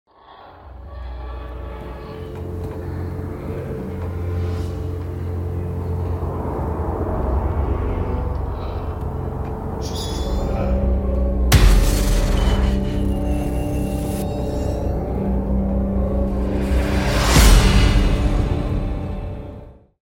🔊 FX mix session for sound effects free download